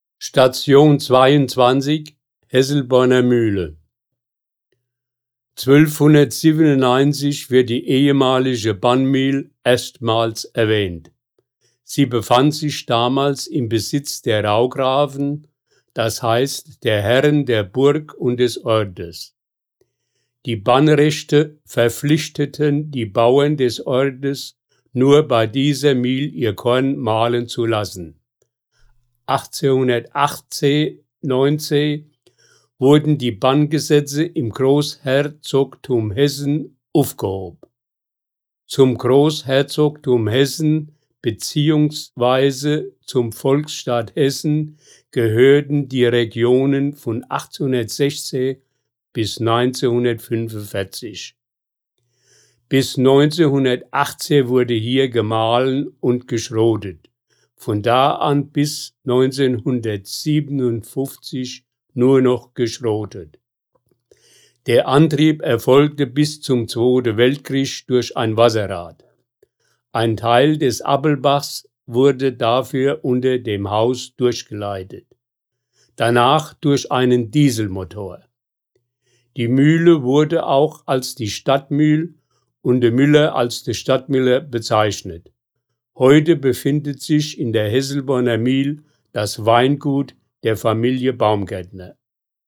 Hier können Sie die Hörfassung der Stationsbeschreibung abspielen!